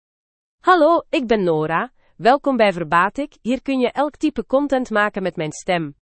FemaleDutch (Belgium)
Nora is a female AI voice for Dutch (Belgium).
Voice sample
Female
Nora delivers clear pronunciation with authentic Belgium Dutch intonation, making your content sound professionally produced.